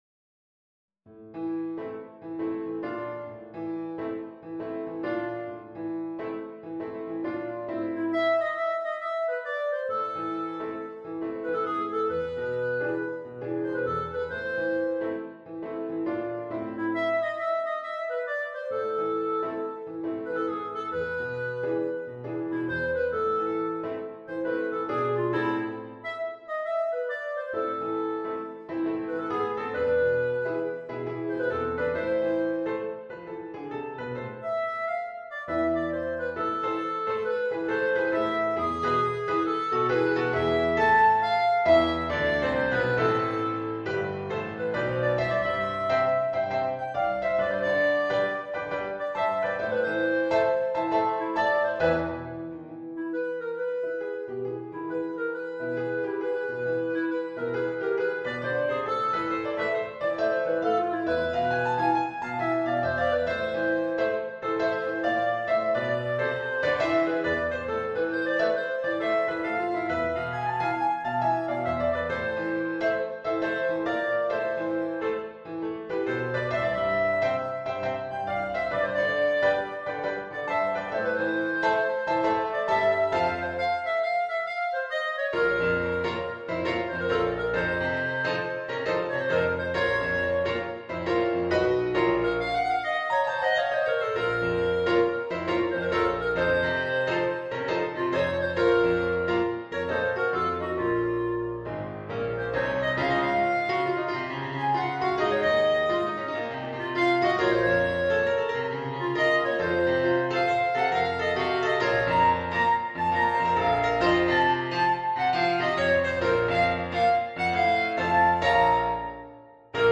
Jazz Arrangement in 5/4 time for Clarinet and Piano.